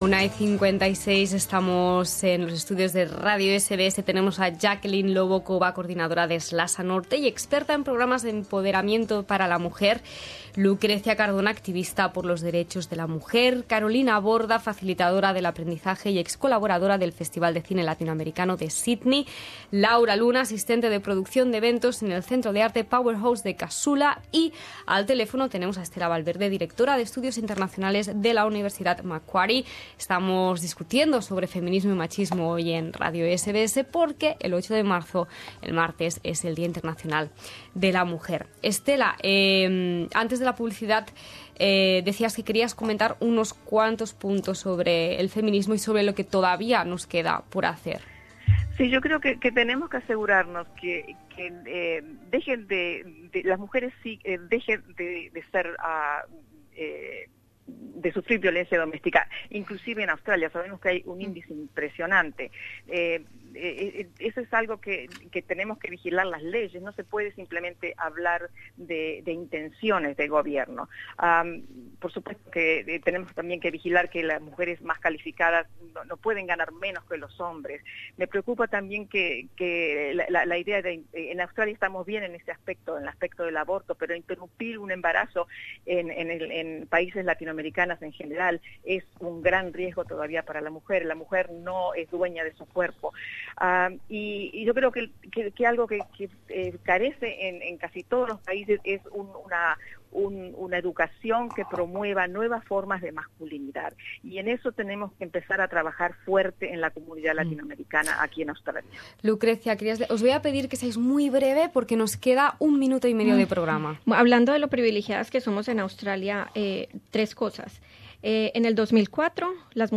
Tercera parte del debate con motivo del Día Internacional de la Mujer. Cuatro mujeres destacadas de la comunidad latina reflexionana sobre el feminismo y machismo en Australia y América Latina.
Juntamos en los estudios a cinco mujeres destacadas de nuestra comunidad para debatir diversos temas relacionados con feminismo.